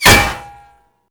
BulletImpact_Metal 01.wav